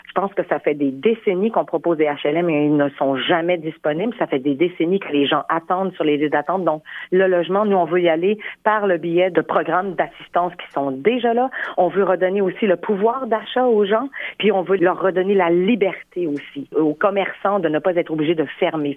C’est ce qu’elle a déclaré ce jeudi sur les ondes du FM 103,3, au lendemain du lancement de la campagne.